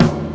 Closed Hats
TOMZ.wav